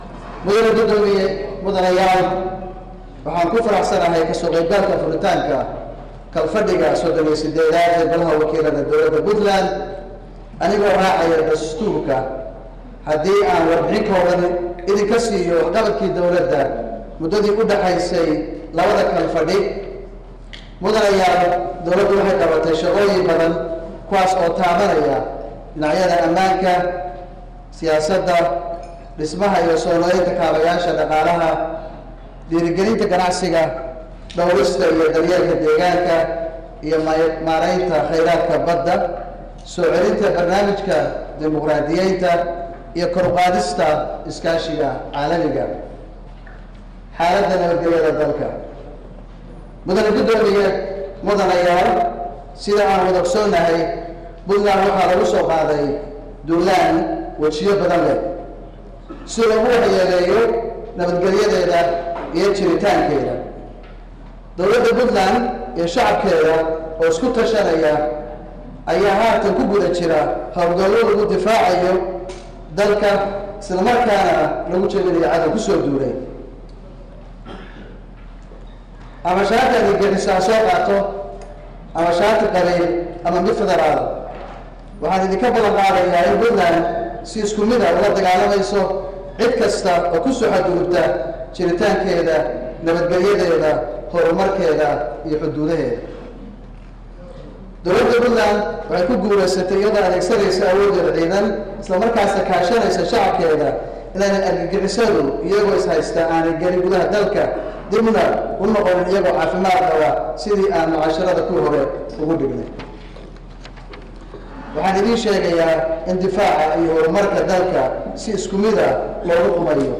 Dhagayso: Khadbadii Madaxweyne Gaas ee Furitaanka Kalfadhigii 38-aad ee Baarlamaanka Dowlada Puntland
Madaxweynaha ayaa khudbad ka jeediyey Munaasabada Furitaanka Kalfadhiga Baarlamaanka, taasoo taabanaysa meelo badan oo ay ka mid tahay Duulaanka lagu soo qaaday magaalada Gaalkacyo, Horumaradii ay dawladu dalka ka samaysay mudadii u dhaxaysay labada kalfadhi.